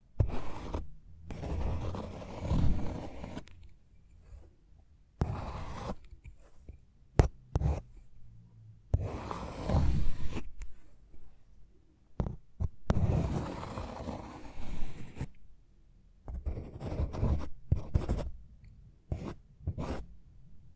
长笔触.wav